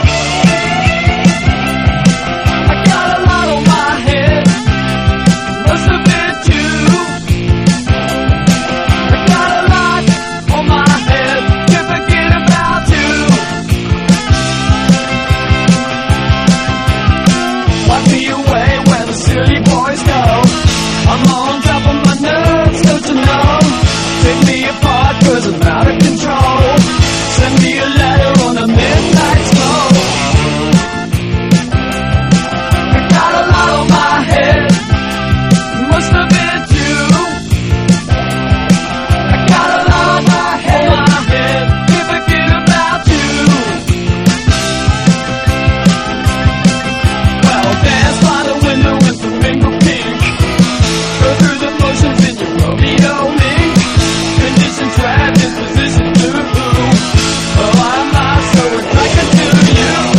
ROCK / 60'S / PSYCHEDELIC ROCK
オルガンの効いたサイケデリックでブルージーな